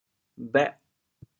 国际音标 [bε?]
bhêh8.mp3